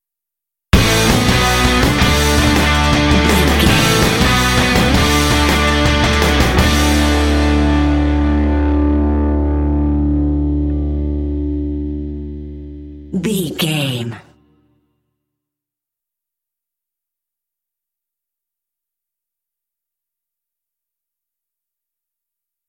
Ionian/Major
Fast
driving
energetic
lively
electric guitar
drums
bass guitar
classic rock
alternative rock